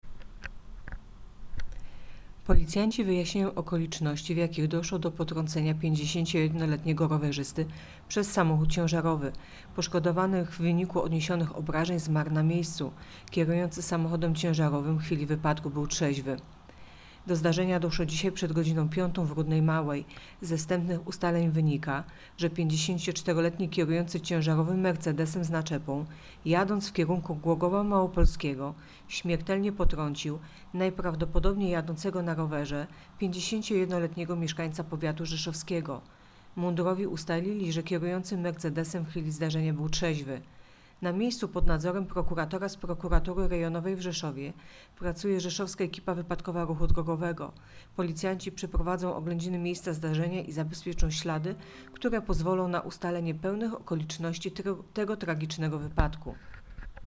Opis nagrania: Nagranie informacji Tragiczny wypadek w Rudnej Małej.